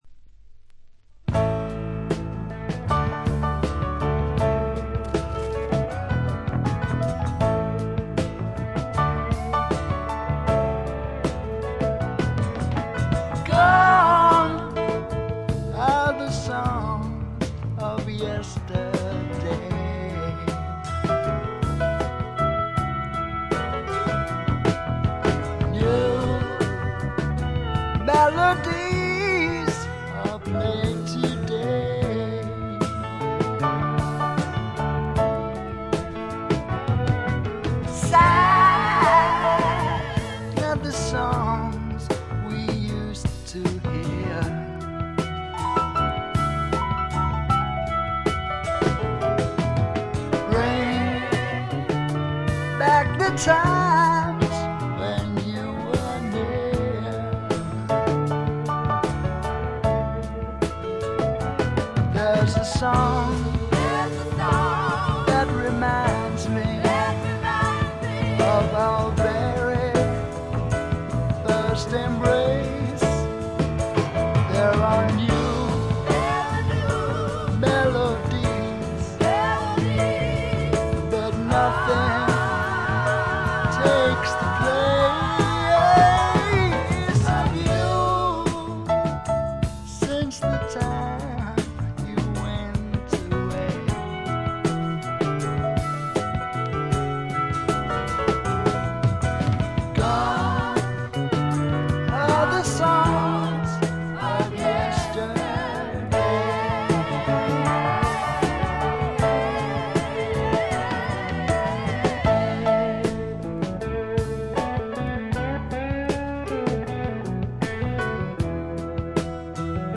わずかなノイズ感のみ。
典型的かつ最良の霧の英国、哀愁の英国スワンプ路線の音作りで、端的に言って「アンドウェラしまくり」です。
搾り出すような激渋のヴォーカルがスワンプ・サウンドにばっちりはまってたまりません。
試聴曲は現品からの取り込み音源です。